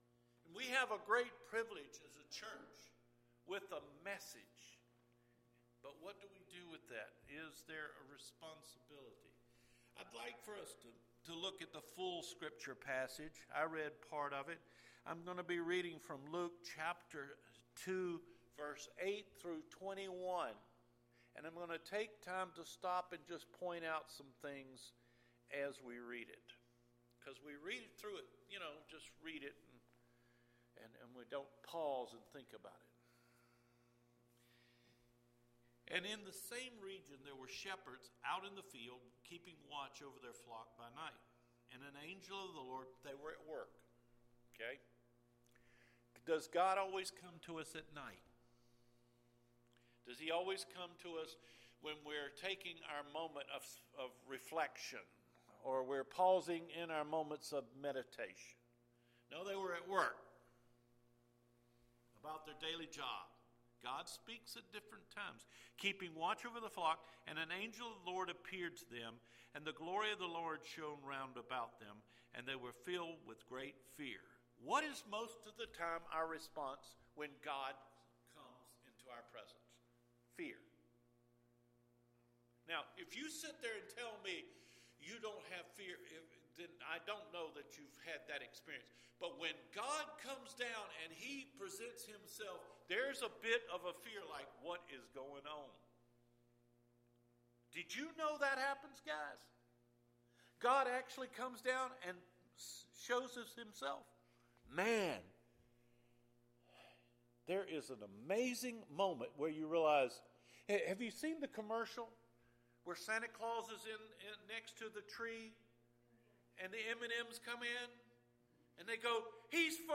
DECEMBER 27 SERMON – THE PRIVILEGE OF THE MESSAGE